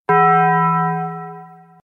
Sound Effects
Taco Bell Bong